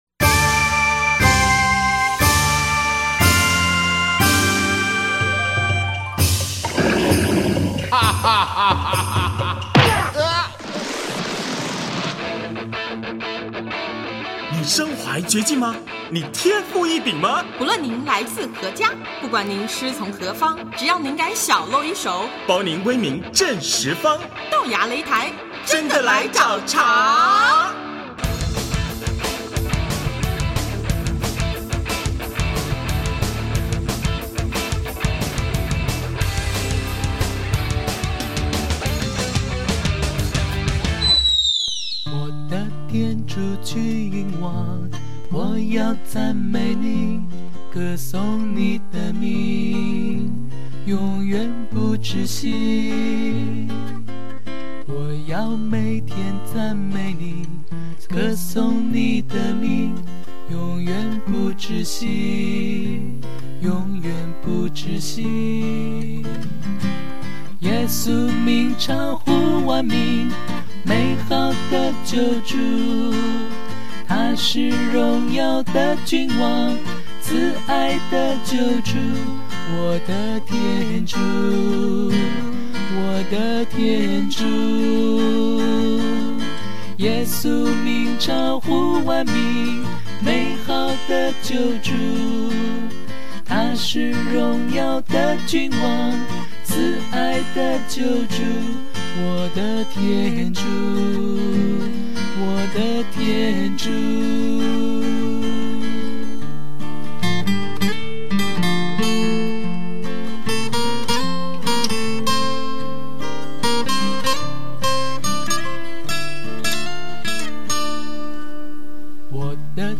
【豆芽擂台】90|专访香柏之歌(二)：把歌声献给天主